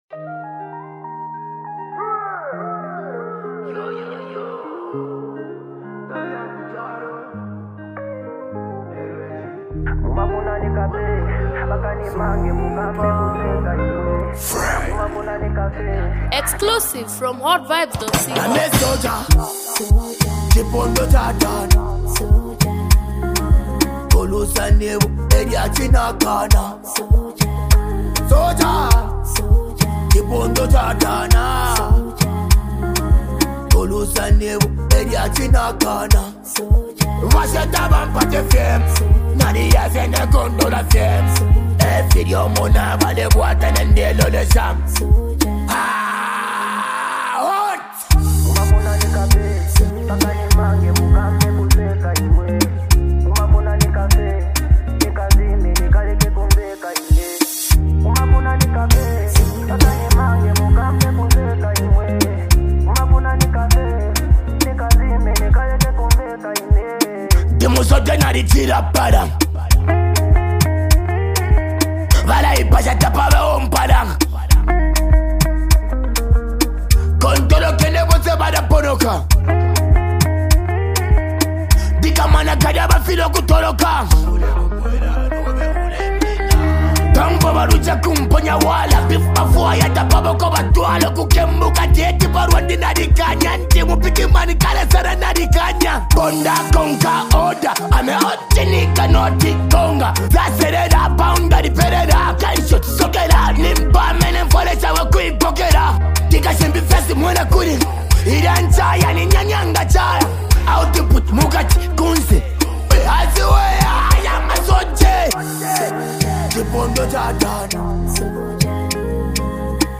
A motivational song